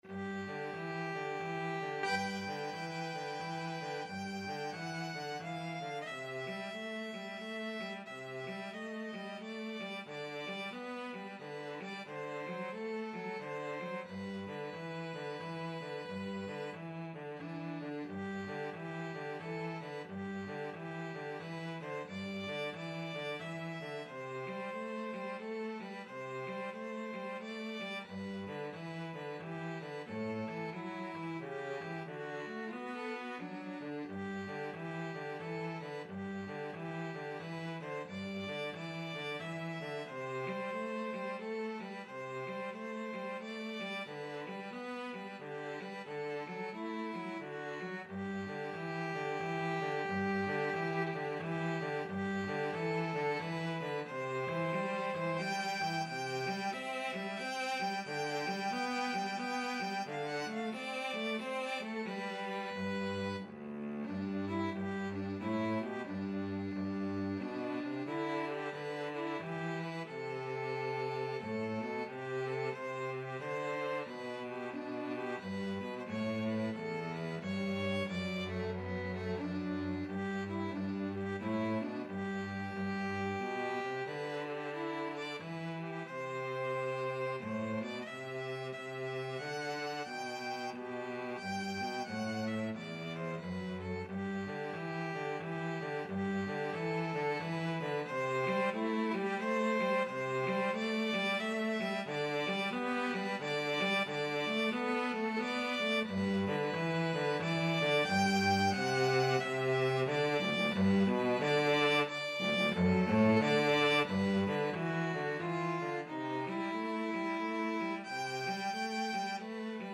Relaxed Swing =c.90
3/4 (View more 3/4 Music)
Christmas (View more Christmas Violin-Cello Duet Music)